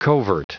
Prononciation du mot covert en anglais (fichier audio)